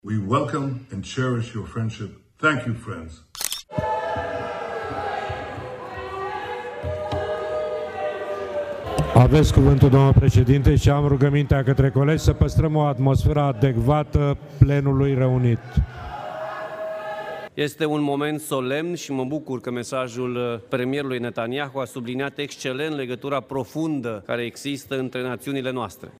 După un mesaj video – de mulțumire – transmis de premierul israelian, Benjamin Netanyahu, opoziția extremistă l-a huiduit pe șeful Camerei Deputaților, Alfred Simonis, când a încercat să ia cuvântul.